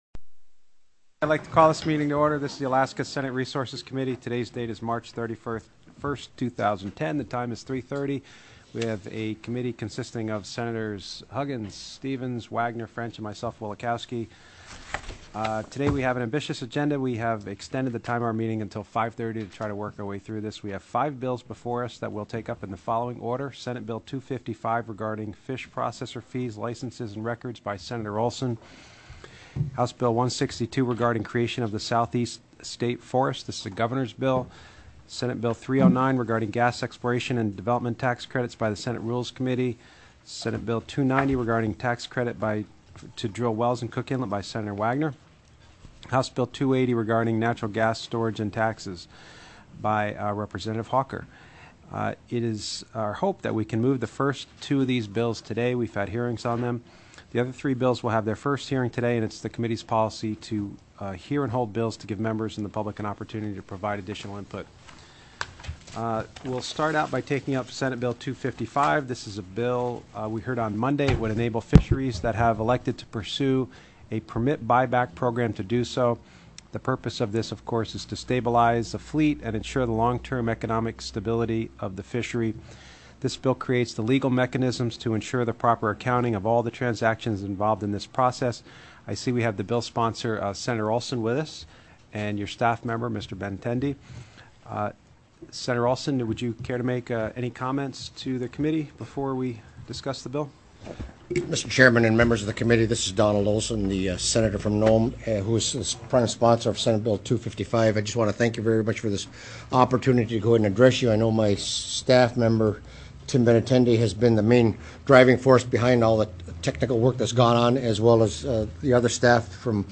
03/31/2010 03:30 PM Senate RESOURCES